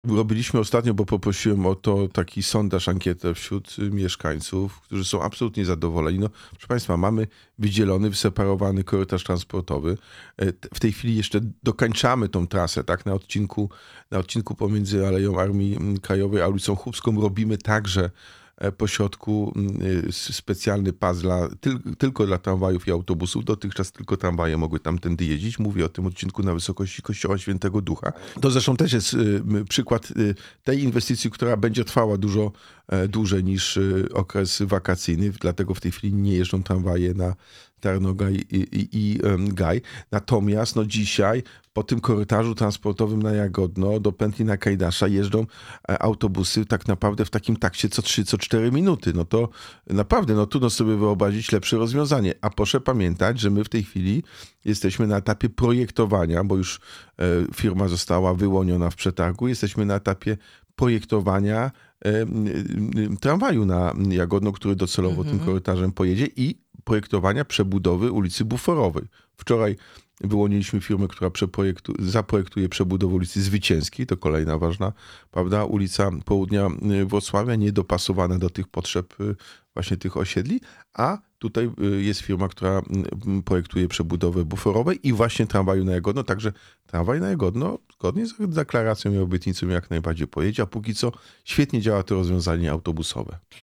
Jacek Sutryk – prezydent Wrocławia był naszym gościem.